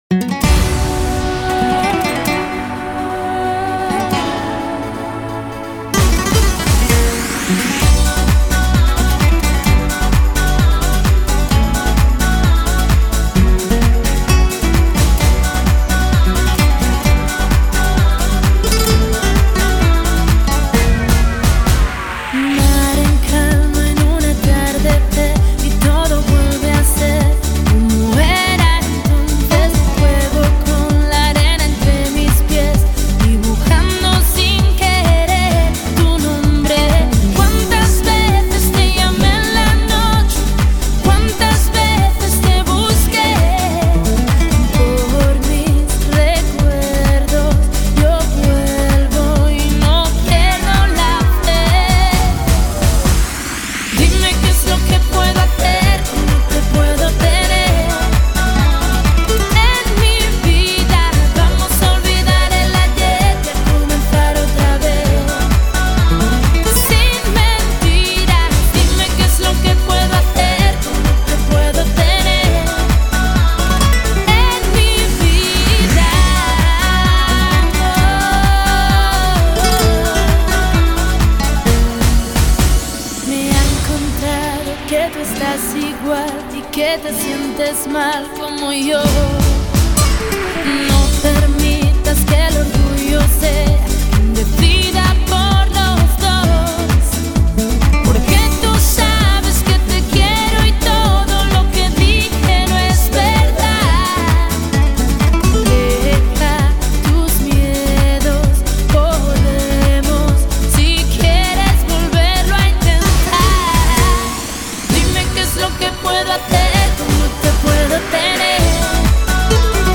Cancion Española